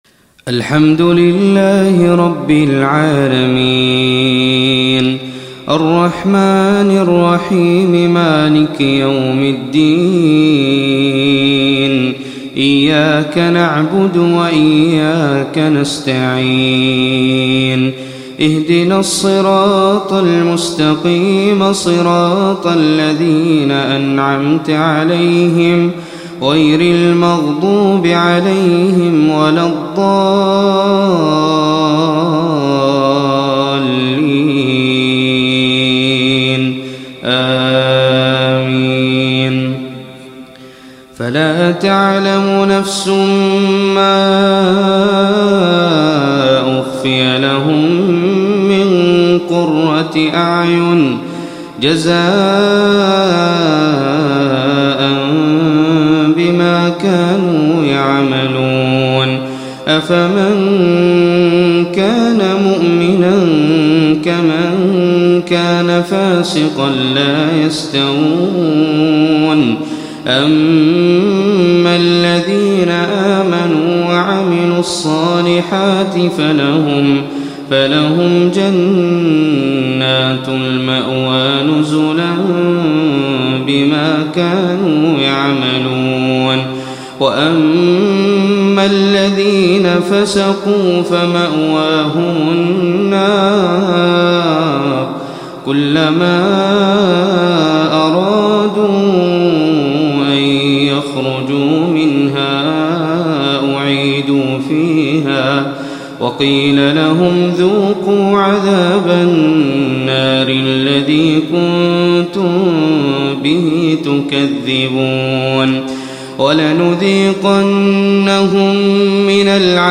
Surat Al Sajda Reciter Hazza Alblushi
Quran recitations